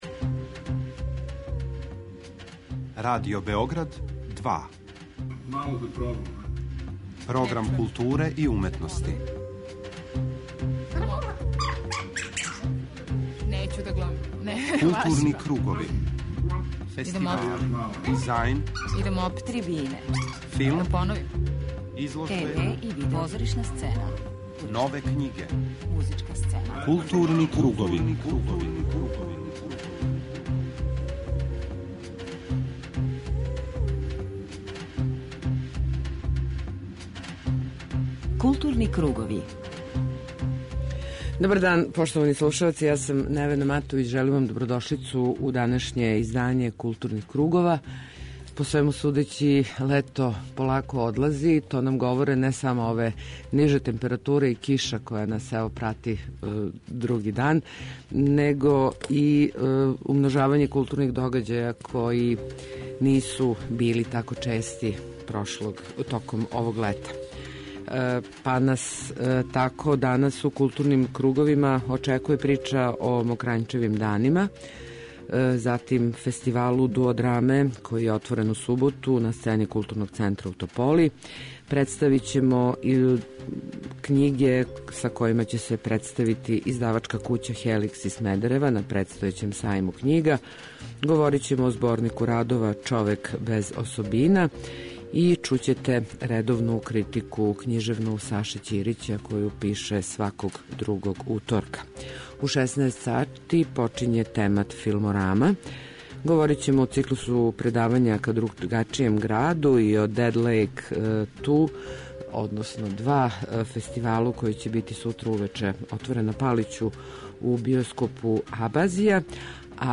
Прошле недеље је, на крову алтернативног културног центра Квака 22, колектив, који себе назива Министарством простора, у оквиру циклуса "Ка другачијем граду", организовао филмску пројекцију и разговор о начину на који се развијају градови данашњице.